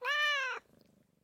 animalia_cat_idle.ogg